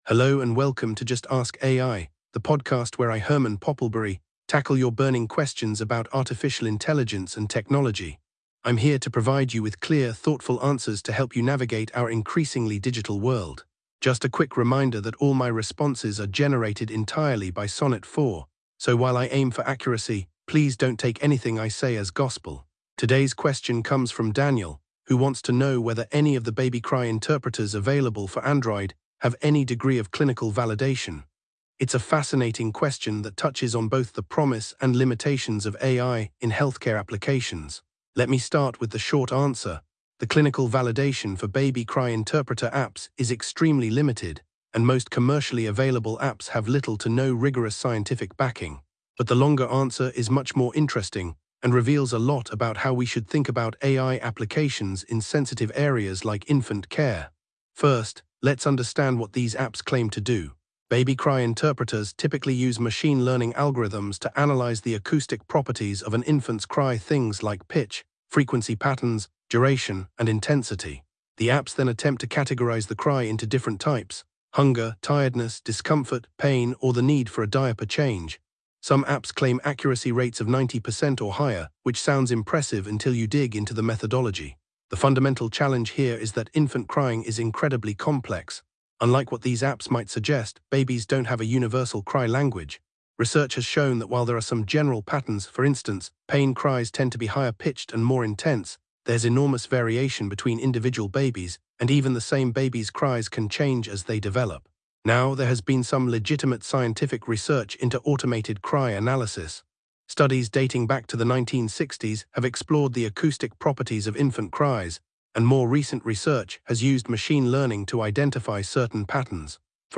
AI-Generated Content: This podcast is created using AI personas.
Hosts Herman and Corn are AI personalities.